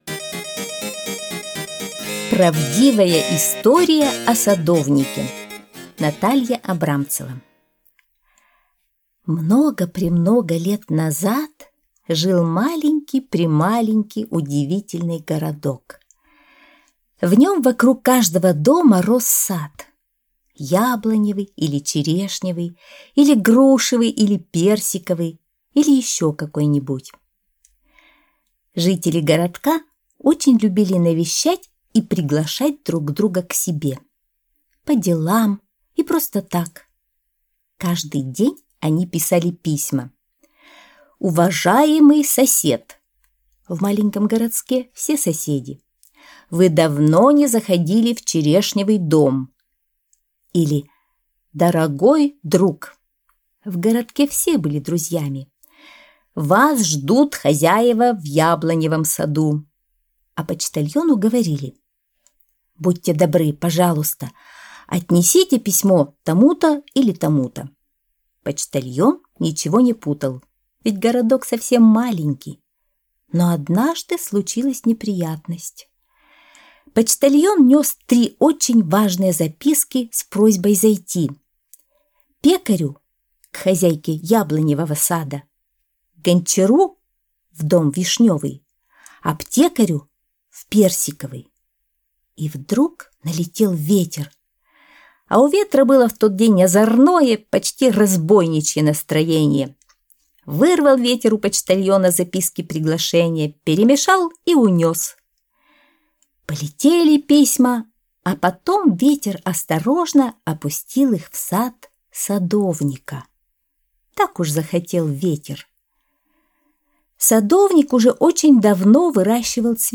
Аудиосказка «Правдивая история о садовнике»